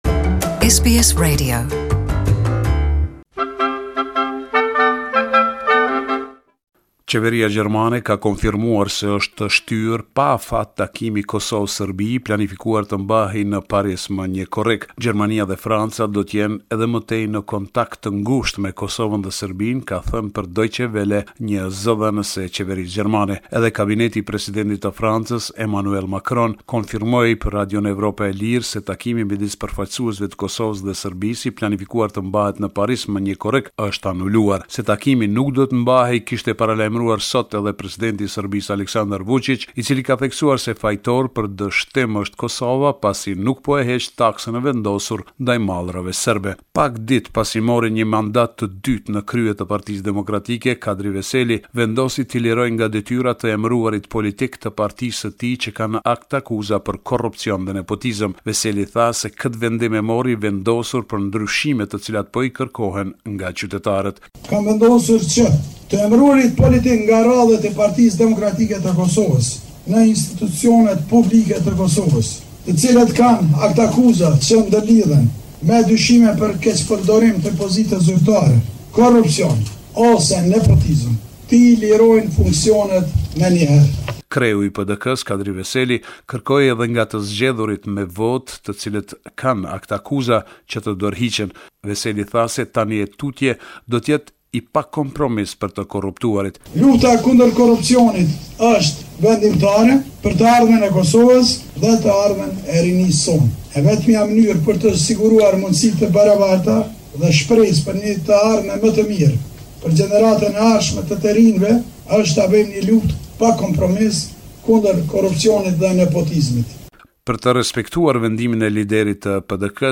This is a report summarising the latest developments in news and current affairs in Kosova